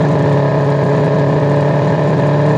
rr3-assets/files/.depot/audio/Vehicles/v6_f1/f1_v6_idle.wav
f1_v6_idle.wav